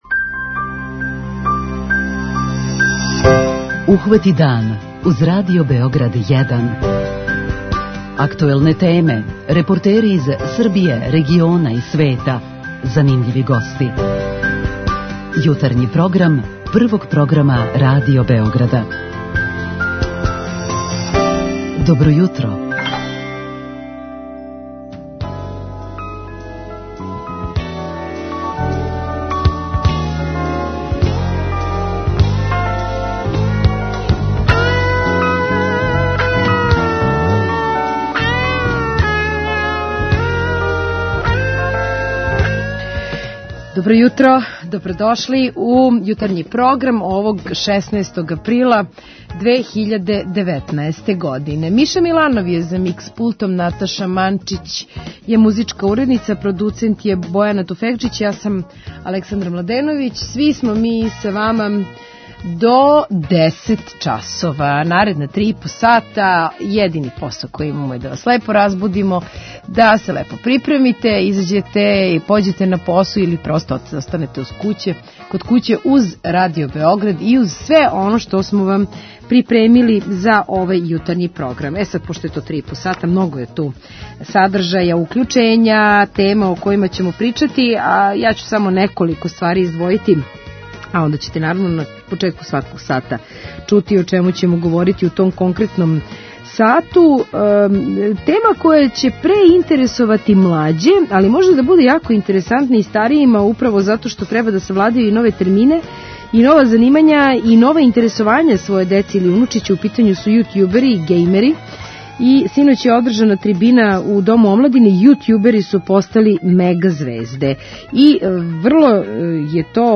Данас креће четврти ИТ караван по школама у Србији током којег ће бити одржана предавања о безбедности на интернету. О ефектима претходних каравана и о плану предавања током овог, разговарамо са државном секретарком у Министарству трговине, туризма и телекомуникација, Татјаном Матић. Са вама у Питању јутра, разговарамо о квалитету меда на нашем тржишту, где купујете мед и колико поверења имате у пчеларе.
преузми : 32.67 MB Ухвати дан Autor: Група аутора Јутарњи програм Радио Београда 1!